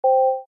An unused alert tone from Bloons Monkey City.
BMC-AlertSound.ogg.mp3